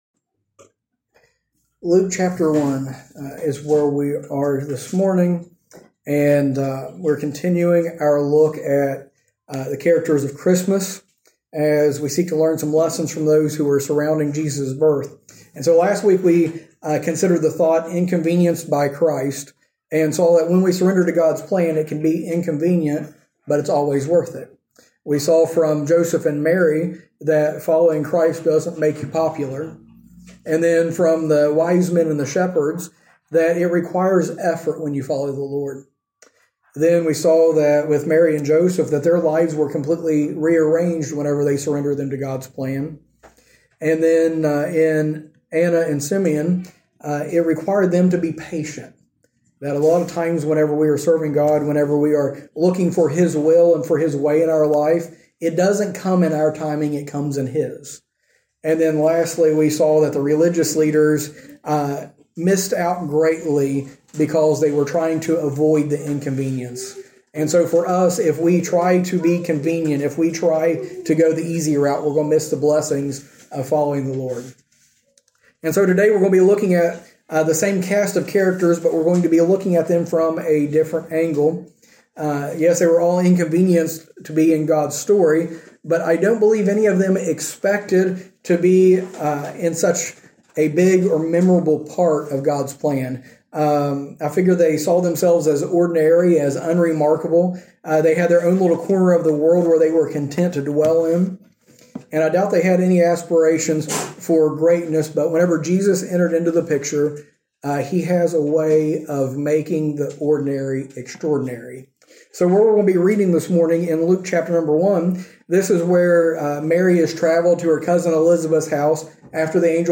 Sermons -